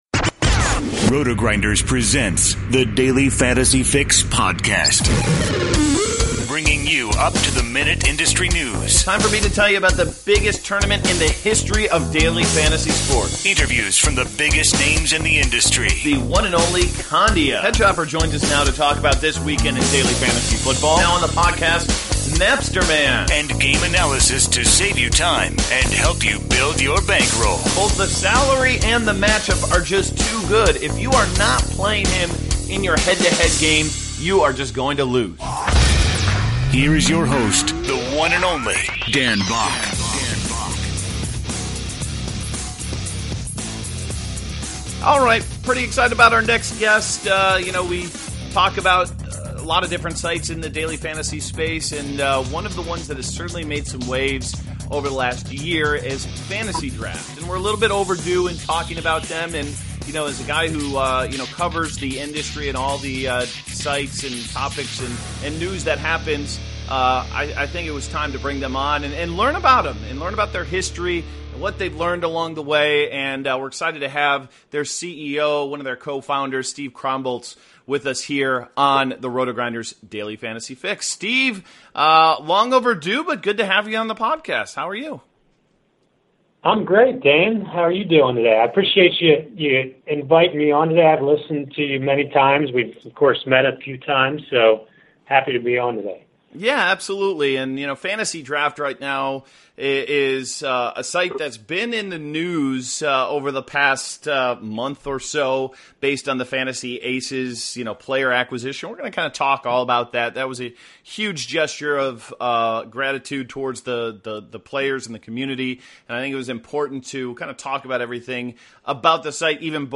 Daily Fantasy Fix: Interview w